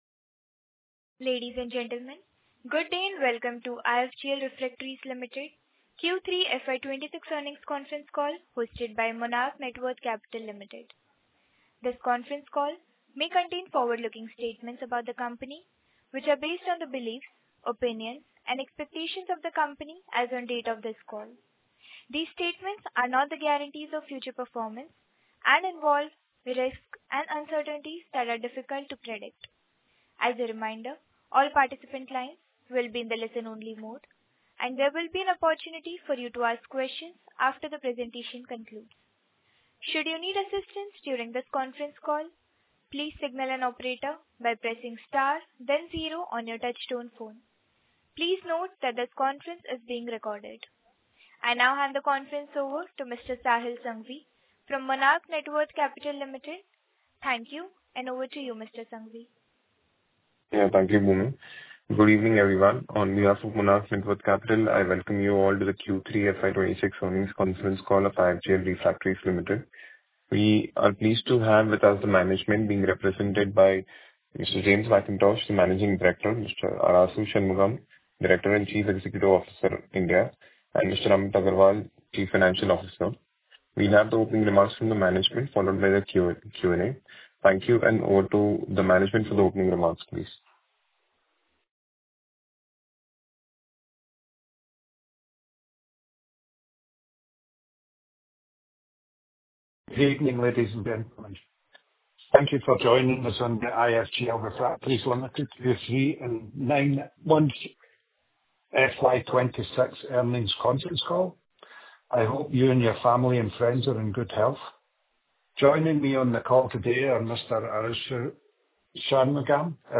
Earnings Call 2026
IFGL-Q3FY26-Earnings-Call-Audio.mp3